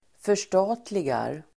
Uttal: [för_st'a:tligar]
f0366rstatligar.mp3